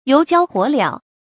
油浇火燎 yóu jiāo huǒ liáo
油浇火燎发音
成语注音 ㄧㄡˊ ㄐㄧㄠ ㄏㄨㄛˇ ㄌㄧㄠˇ